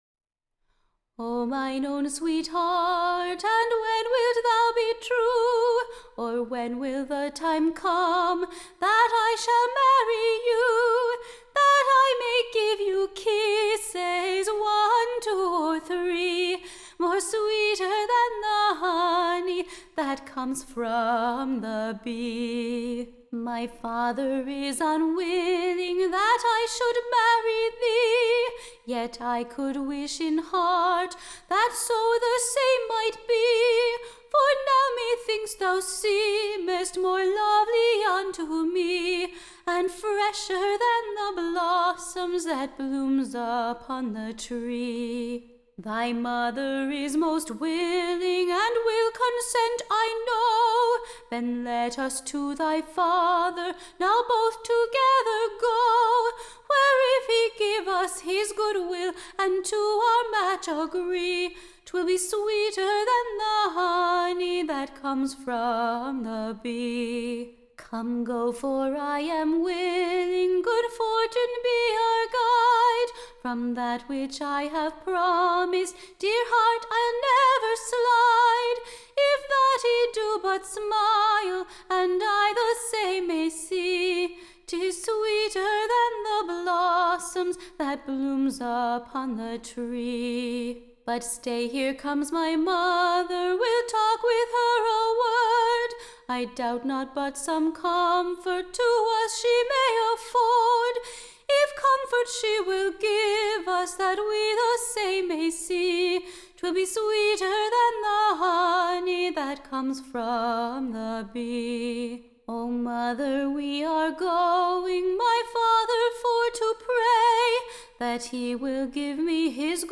Recording Information Ballad Title A Country new Iigge betweene Simon and Susan, to be sung in merry / pastime by Bachelors and Maydens.